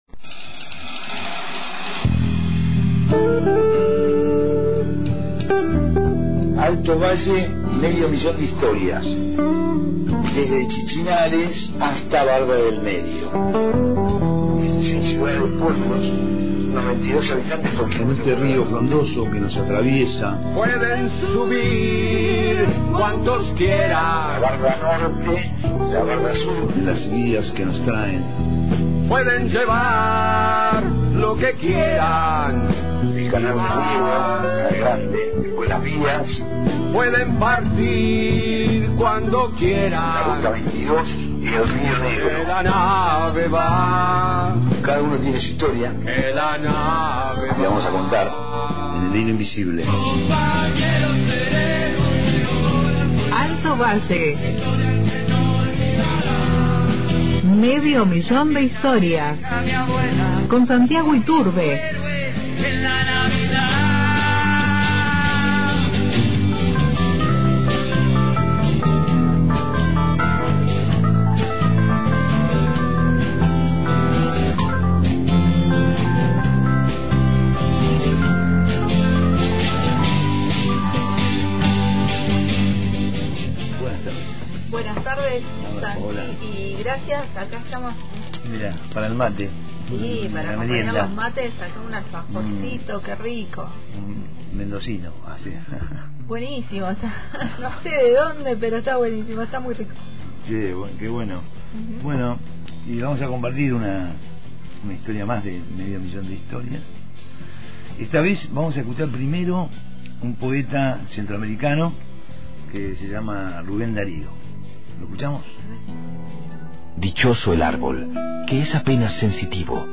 Además, sonó Ser Retro , banda local que reinterpreta música de los 80 con gran calidad.